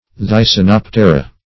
Thysanoptera \Thy`sa*nop"te*ra\, n. pl. [NL., from Gr. ? a